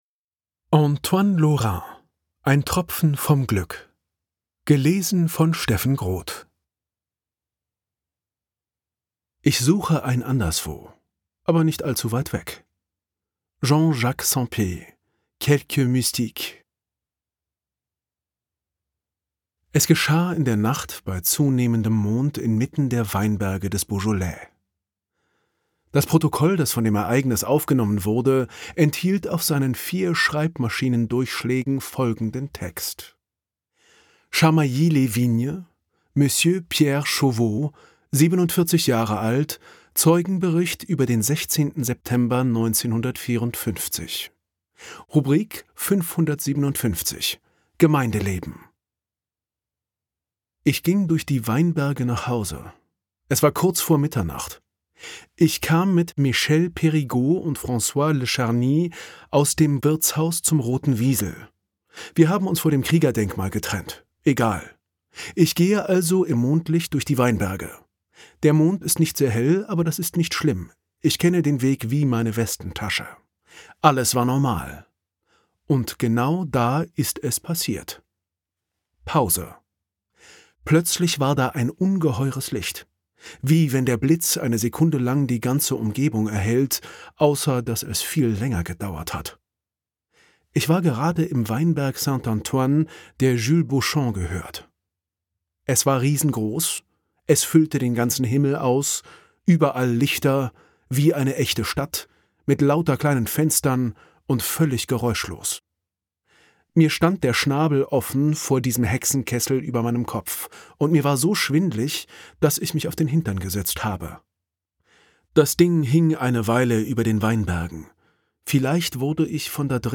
Ungekürzte Lesung mit Steffen Groth (5 CDs)
Steffen Groth (Sprecher)